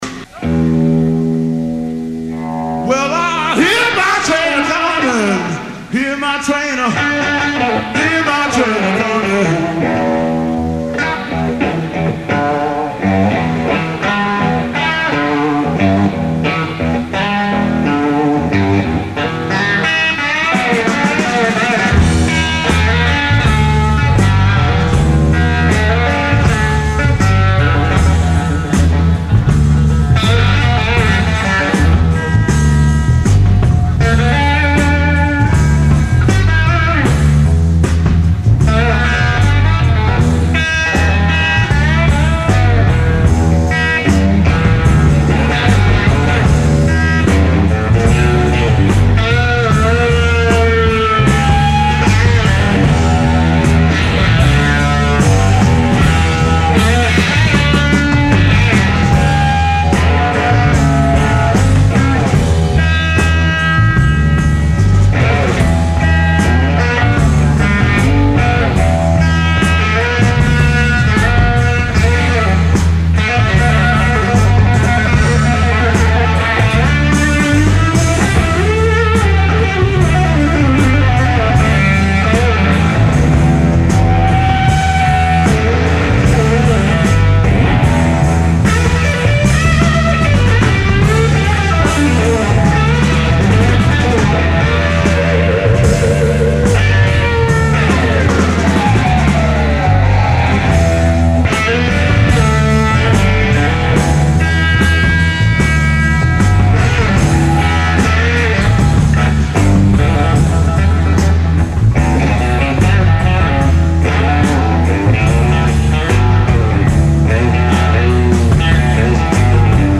live Memorial Day 1970 Berkeley CA
bass
drums